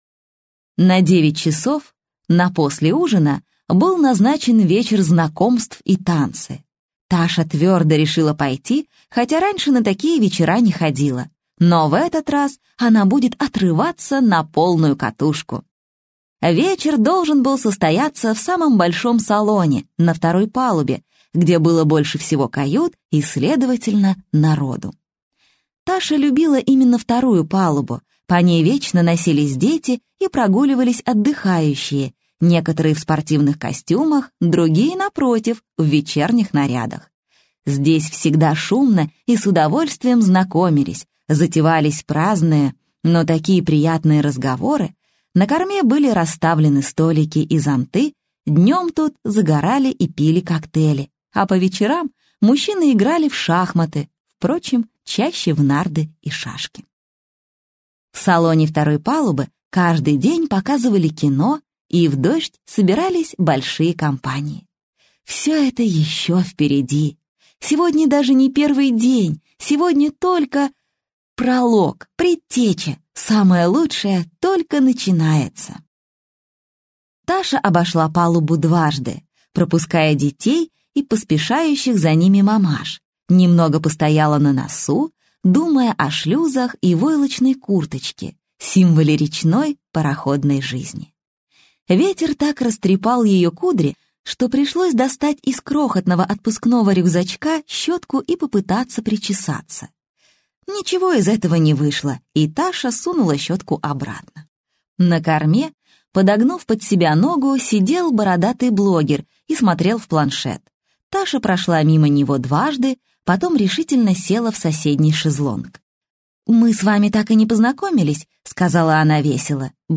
Аудиокнига Ждите неожиданного - купить, скачать и слушать онлайн | КнигоПоиск